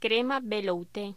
Locución: Crema velouté
voz